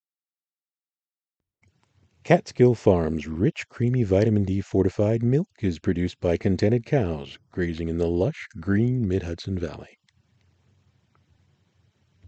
Newbie ACX narrator humbly submitting my untreated voice sample for frank feedback
Audiobook Mastering
I’m using an Apogee Mic+ connected via USB to a Samsung laptop running Windows 11 and Audacity 3.3.3.